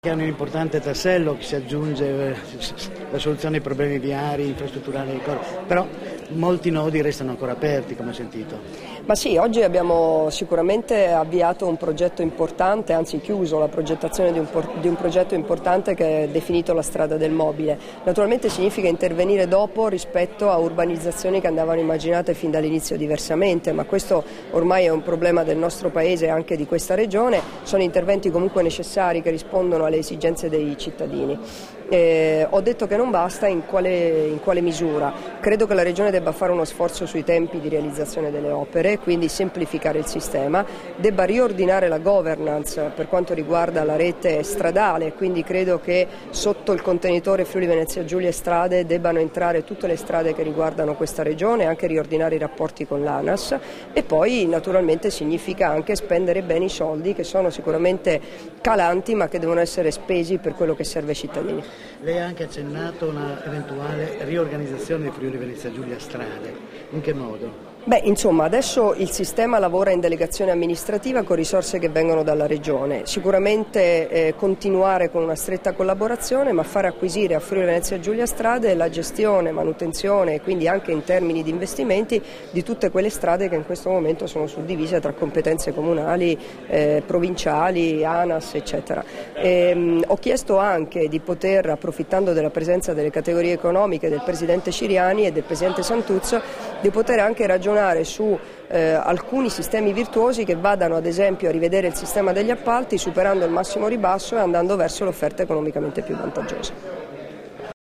Ascolta le dichiarazioni di Debora Serracchiani rilasciate in occasione dell'annuncio del prossimo avvio di un "mega piano" per potenziare la viabilità della Zona pordenonese del Mobile, a Pordenone il 12 giugno 2013 - Formato MP3 [1730KB]